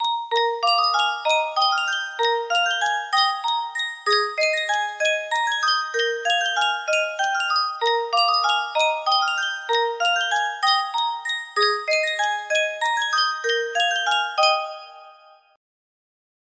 Sankyo Music Box - Chopin Grande Valse Brillante GAK music box melody
Full range 60